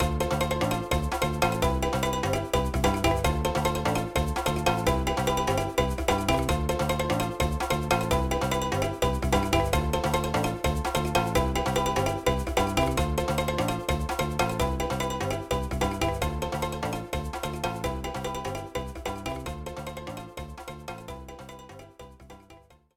Source Ripped from the game's sound files.